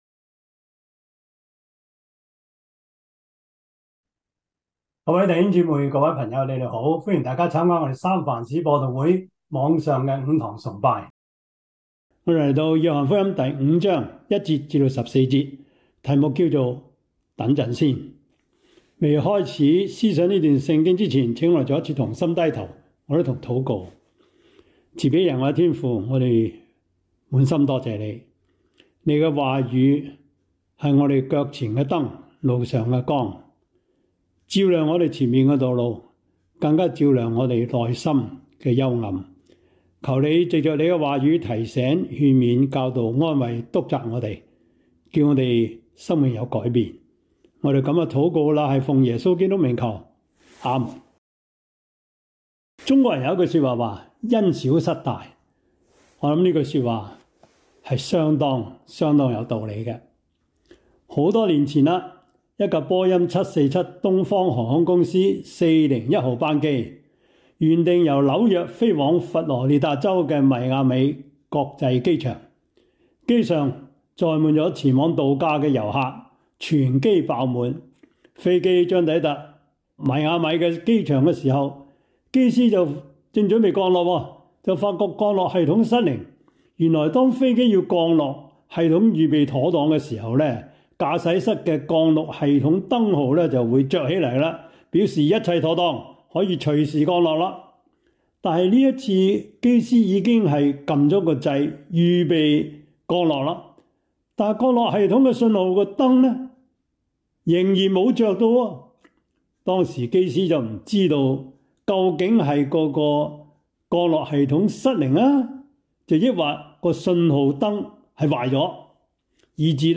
約翰福音 5:1-14 Service Type: 主日崇拜 約翰福音 5:1-14 Chinese Union Version
Topics: 主日證道 « 如何回應神國的福音？